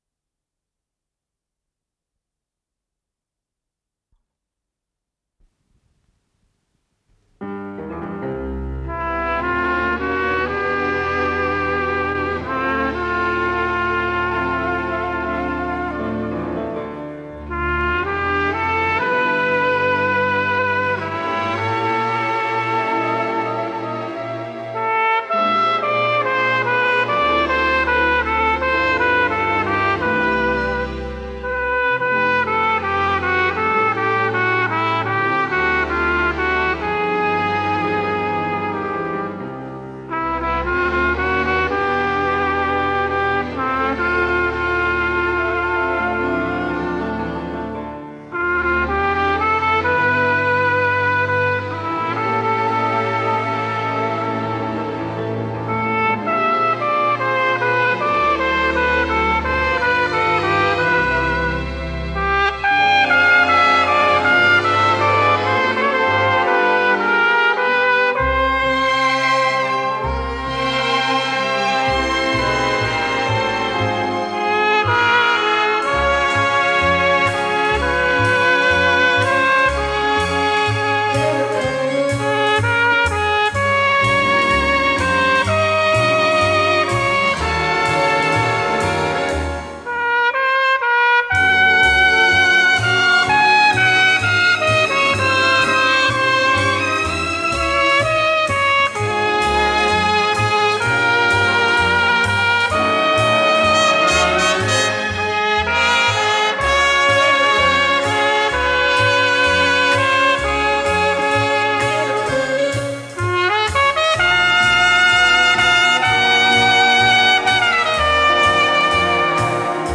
с профессиональной магнитной ленты
труба
ВариантДубль моно